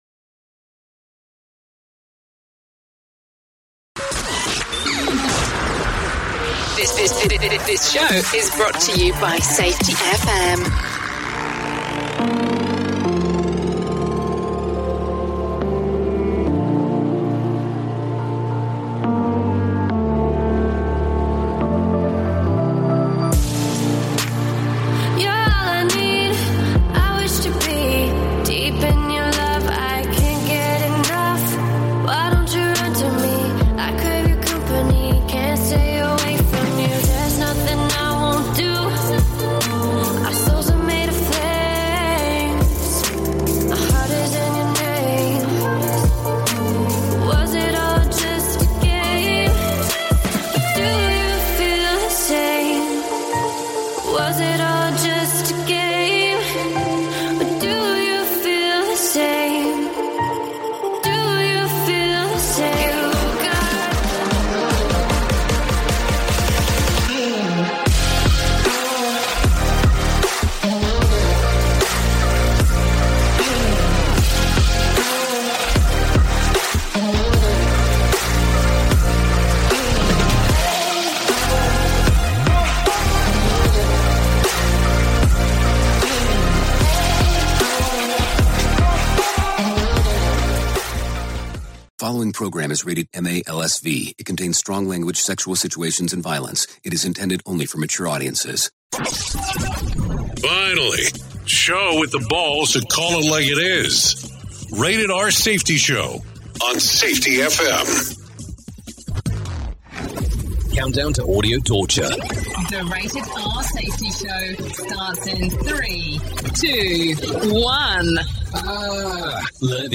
Welcome to Thursday’s broadcast of chaos and clarity.